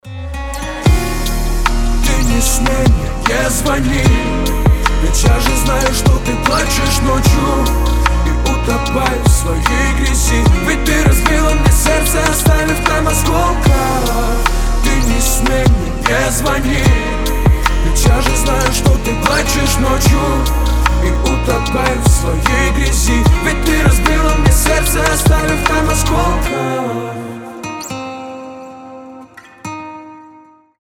• Качество: 320, Stereo
лирика
грустные